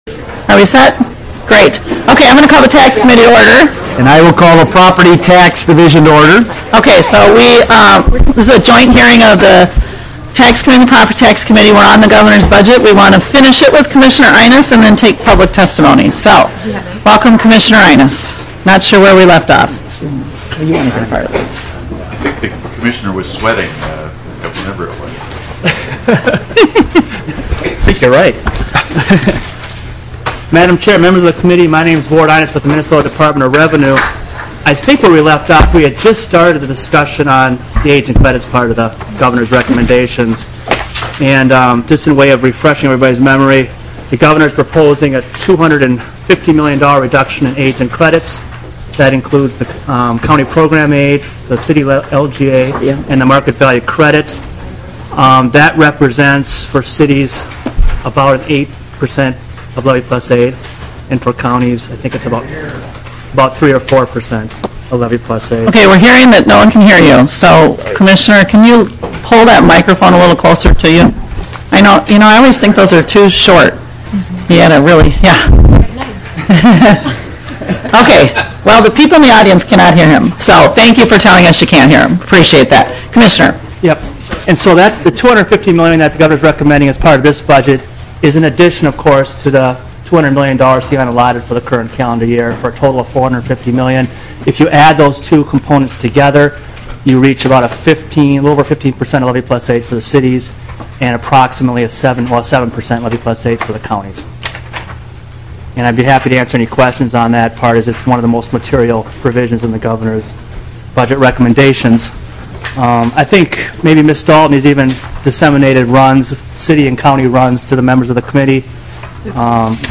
01:44 - Gavel, and discussion of the governor's tax proposals.
Show Full Schedule Agenda: HFXXX Governor's tax proposals Public Testimony will be taken